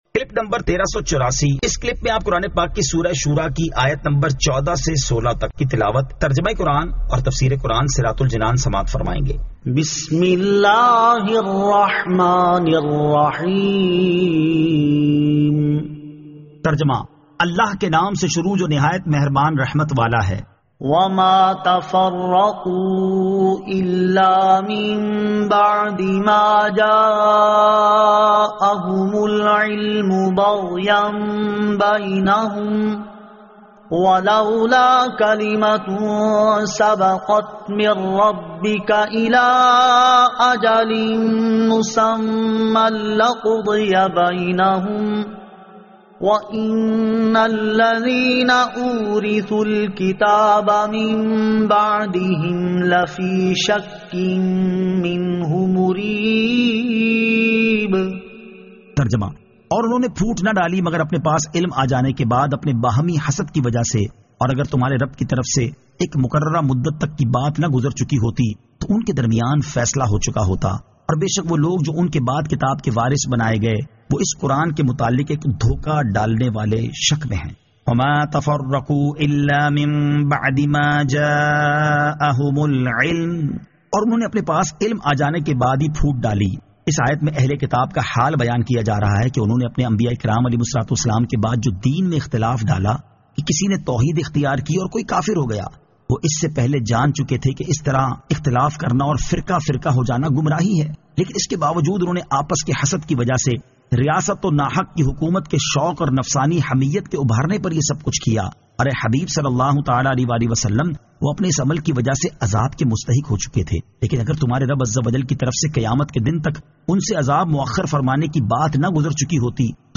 Surah Ash-Shuraa 14 To 16 Tilawat , Tarjama , Tafseer